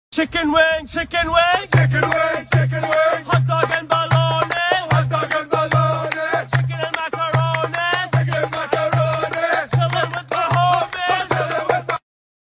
Dance Song meme
Funny sound effects for comedy free download mp3 Download Sound Effect Home